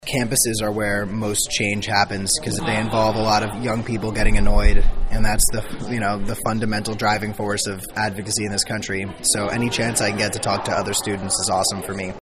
K-State was the latest destination for Kasky as he participated in a public question-and-answer presentation called “Tools for a Movement” at the K-State Student Union Tuesday.
Kasky-speaking-at-colleges.mp3